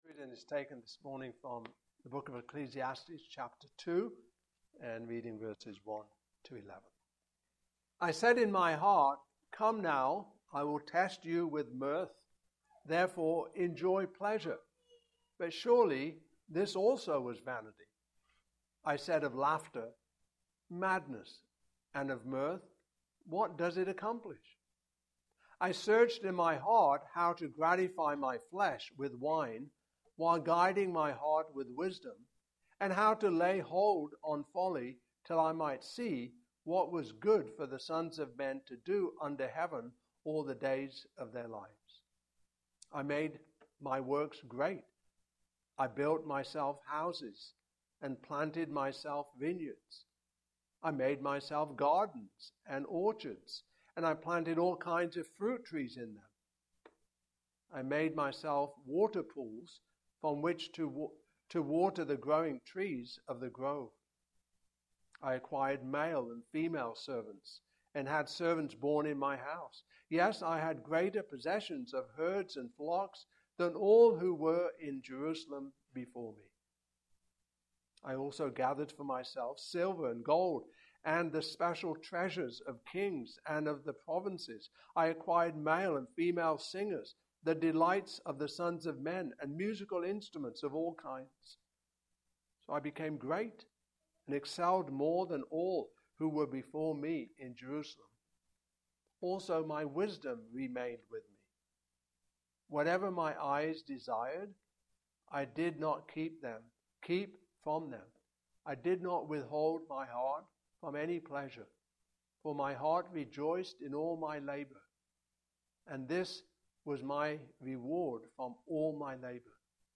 The Book of Ecclesiastes Passage: Ecclesiastes 2:1-11 Service Type: Morning Service « Creation